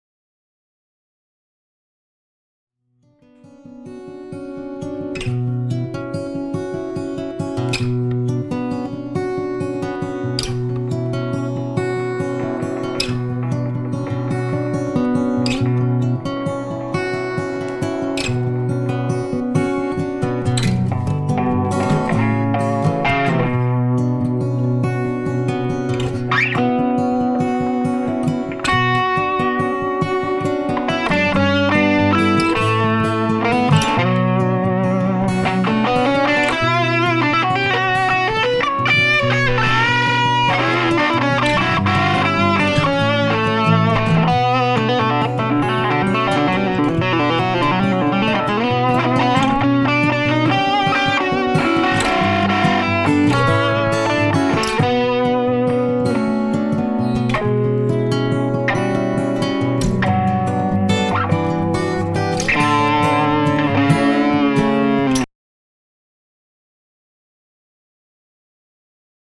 It offers an output of 60 watts and a choice of three tones, from completely clean to evil grinding distortion.
A wee blast (it’s not even turned up that loud!)
improvisation1.mp3